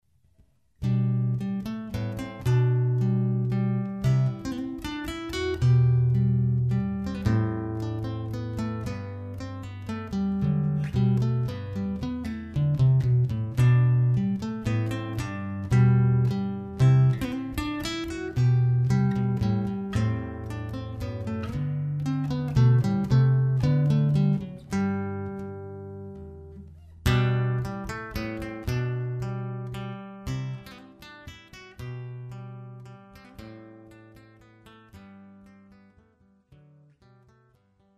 Gitarrist
Menuett (Klassik)